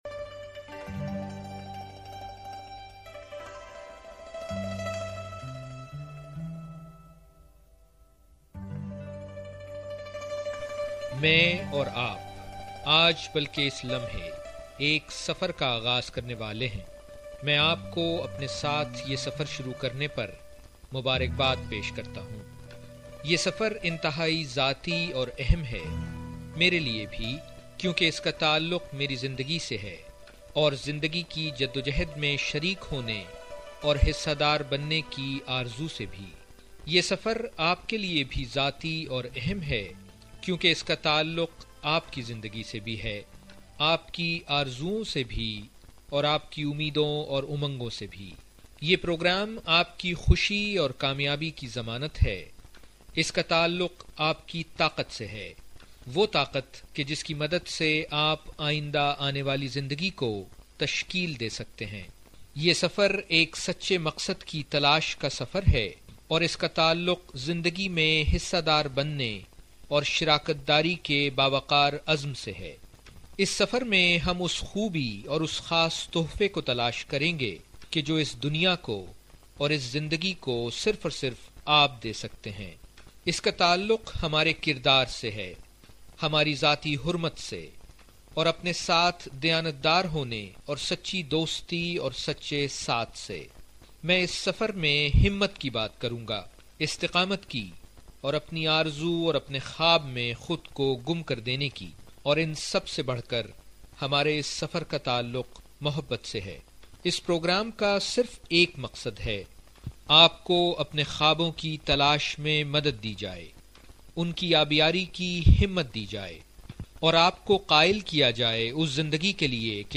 Introduction to a multi segment audio workshop The Power of Purpose "Maqsadiat ki Taqat" An outstanding Urdu Podcast of a Leadership Workshop, the first of its kind. It takes its audience to the journey of self exploration while they learn to write their Mission Statement. In this journey of our quest for self exploration, we will come across many universal values such as justice, truth, empathy and honesty.